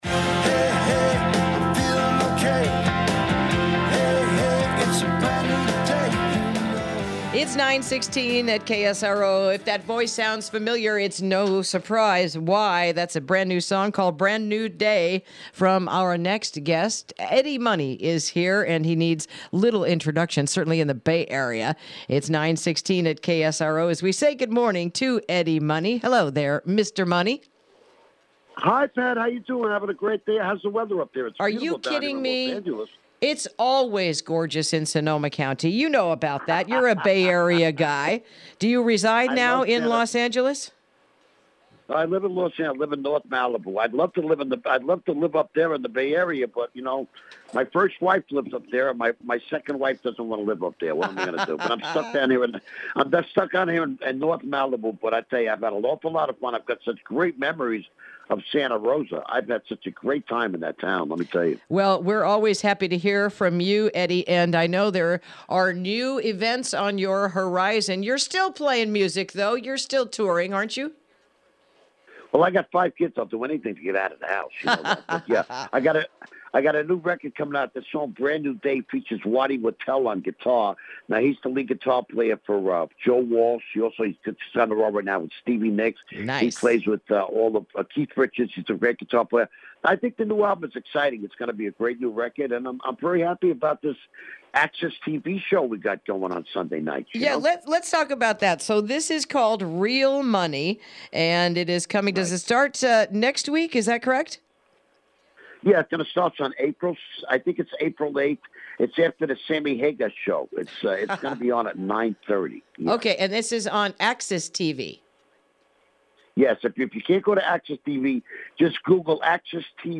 Interview: Eddie Money’s New Family Reality Show “Real Money”